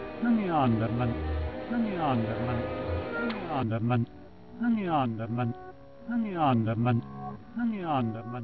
Mumblings at the end of the song